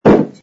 sfx_put_down_glass05.wav